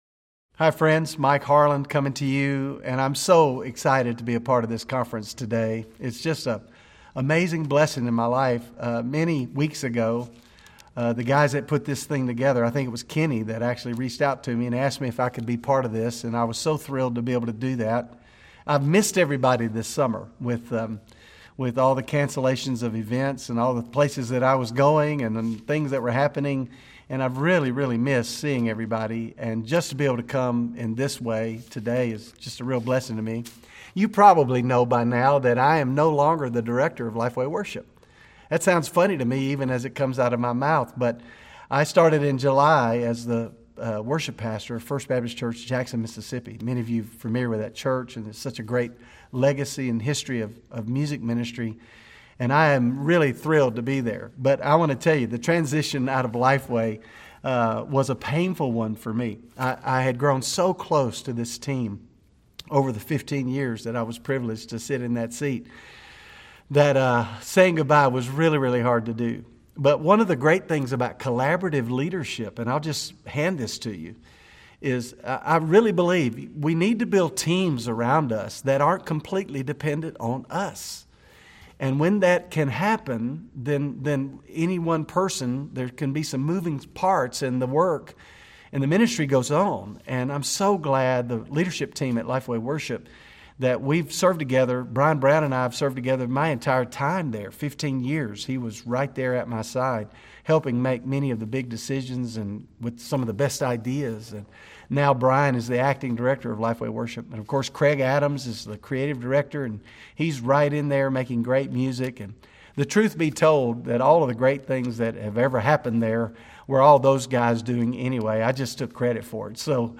Keynote Address